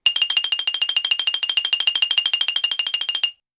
Звуки подмигивания
Быстрое мультяшное моргание глаз с звуком